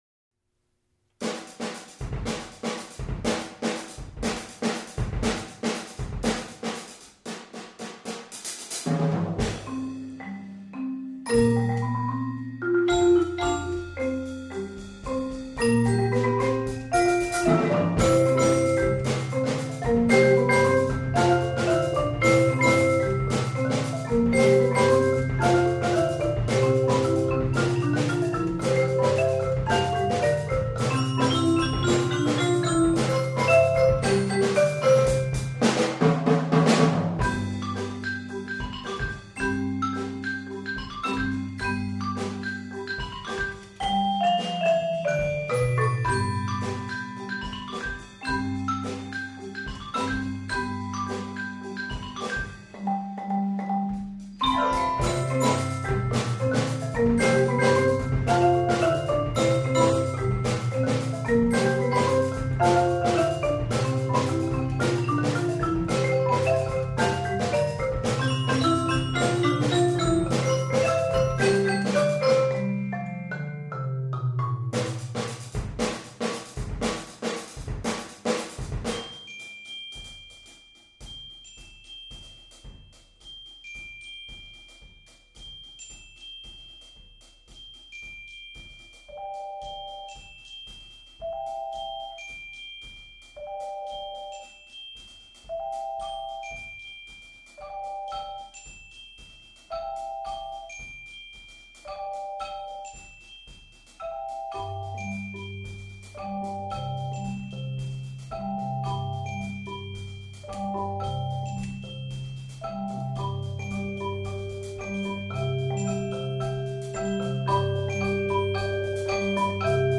Genre: Percussion Ensemble
# of Players: 6 + Electric Bass
Bells
Xylophone
Vibraphone
Marimba
Electric Bass
Drum Set